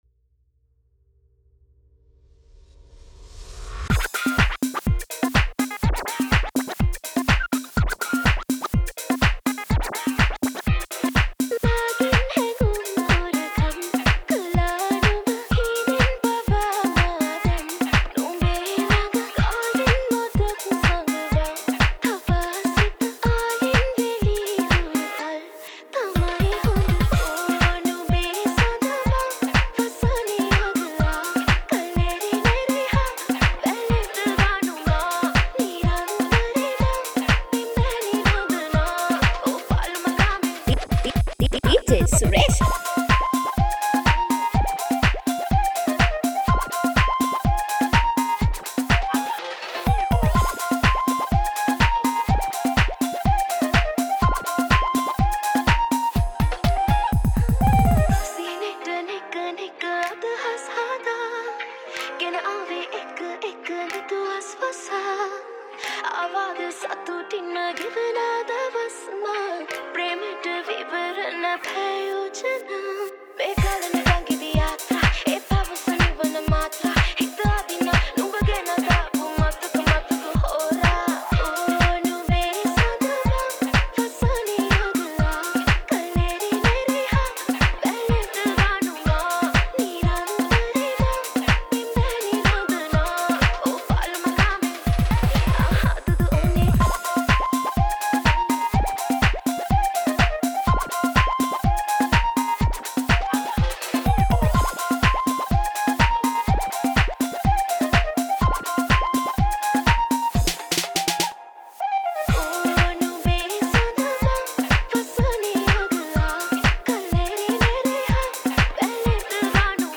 Deep House Remix